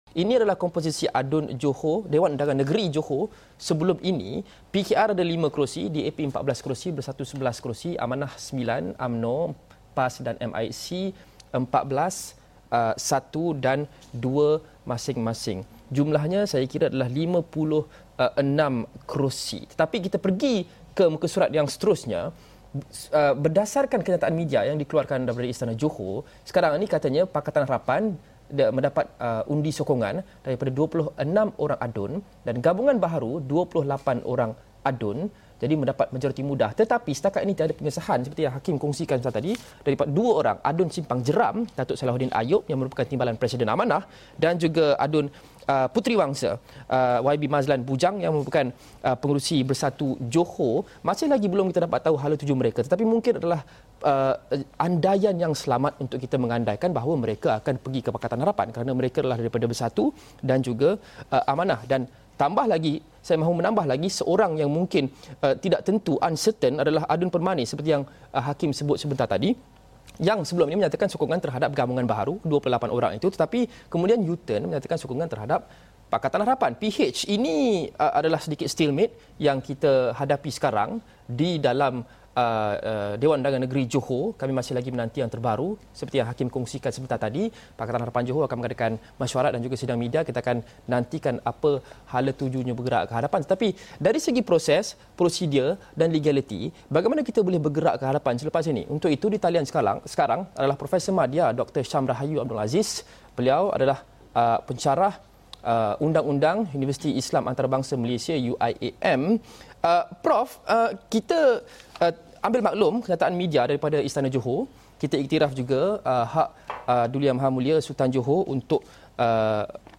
Bersedia di talian bagi mengulas mengenai konsep kerajaan campuran mengikut undang-undang.